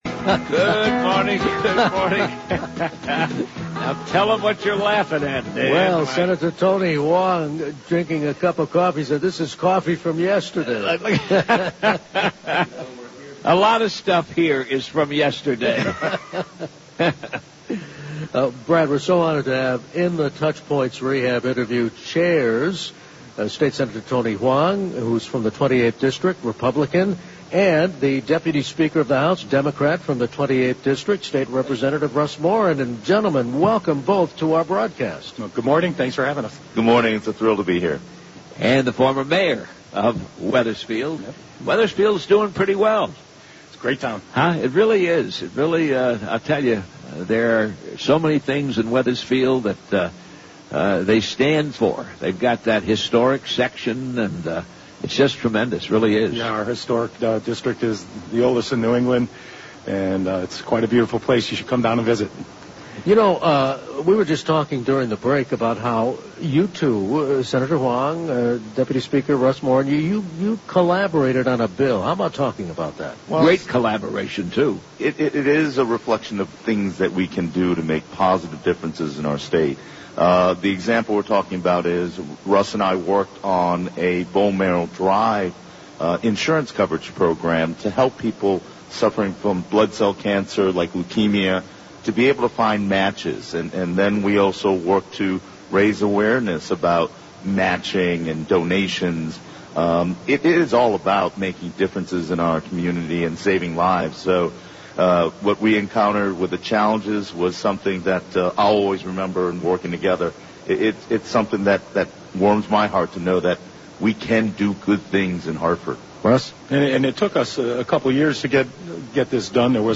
Sen. Tony Hwang and Rep. Russ Morin interviewed
live from the state capitol